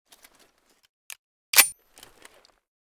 w99_unjam.ogg